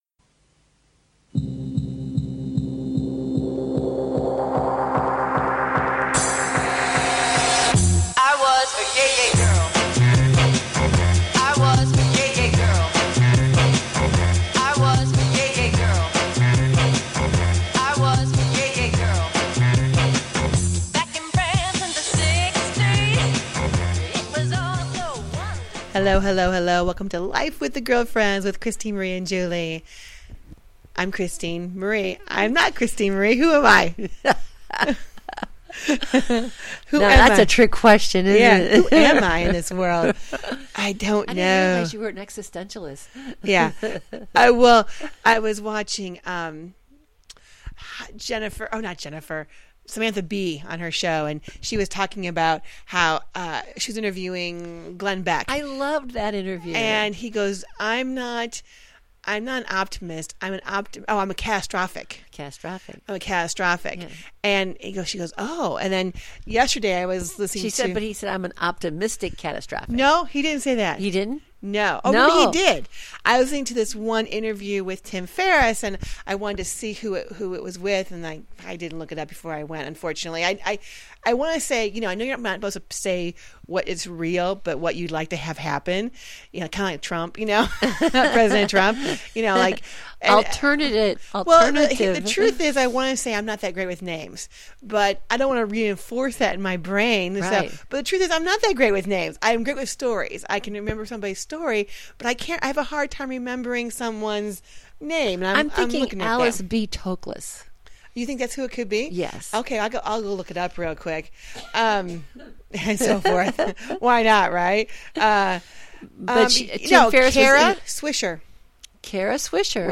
Talk Show
The conversations are candid, honest, and more so - fun!